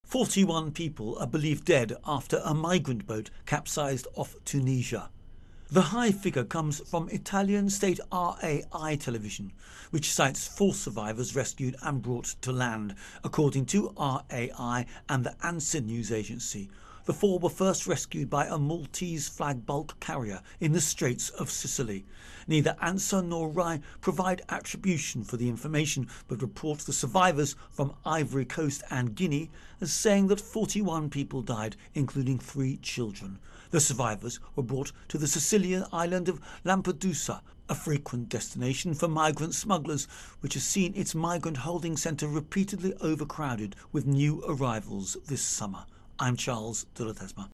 reports on Migration Italy Capsizing.